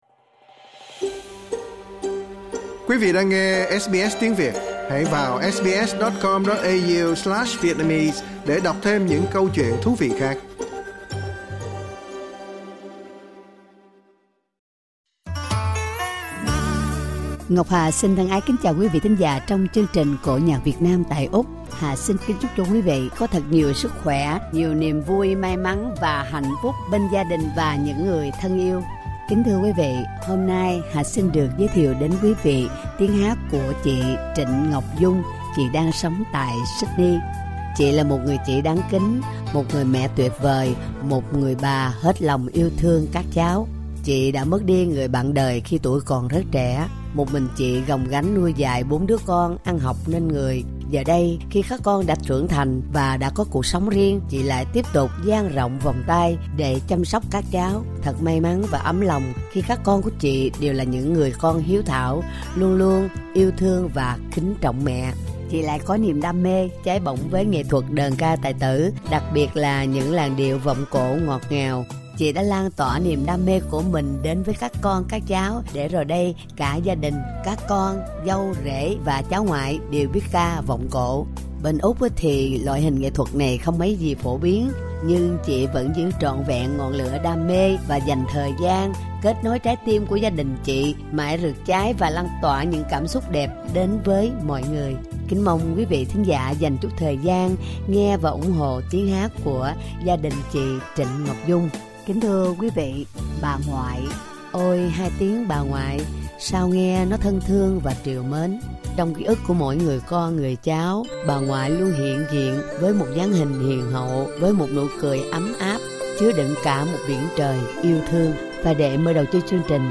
READ MORE SBS Việt ngữ Xin mời quý vị nghe ca cảnh 'Nhớ Ngoại'